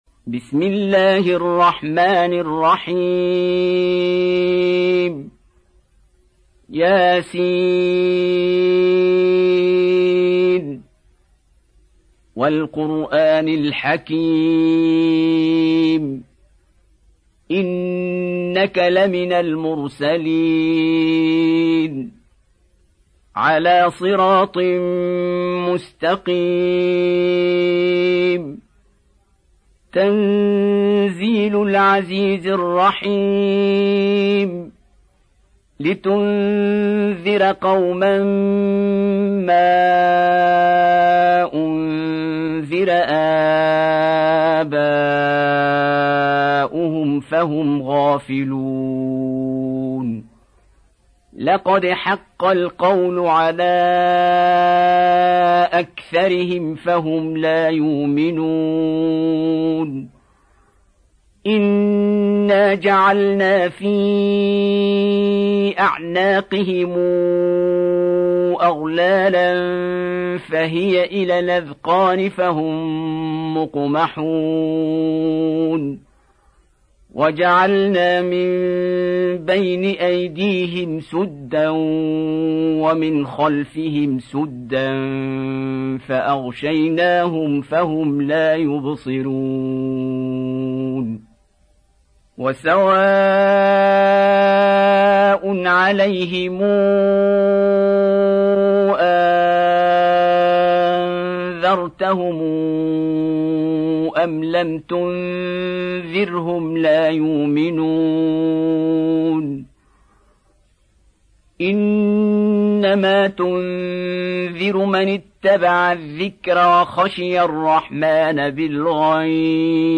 Surah Yaseen MP3 Download By Abdul Basit Abdul Samad. Surah Yaseen Beautiful Recitation MP3 Download By Qari Abdul Basit in best audio quality.